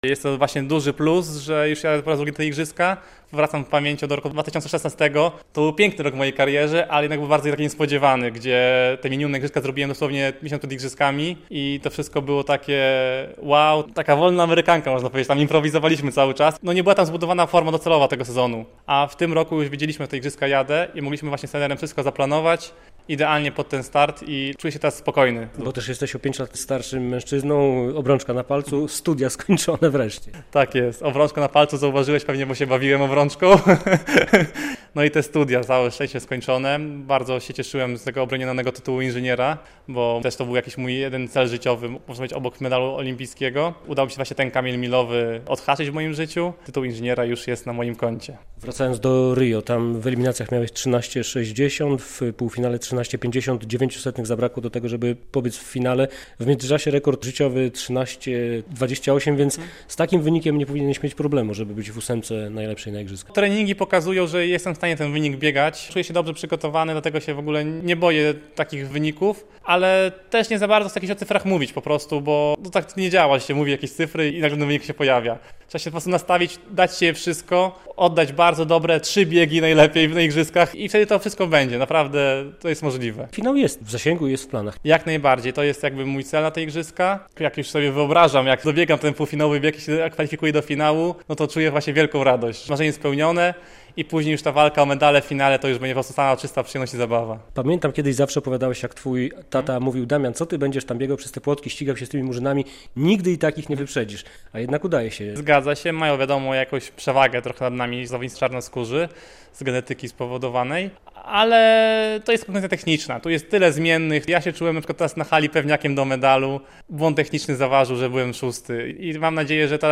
olimpijczyk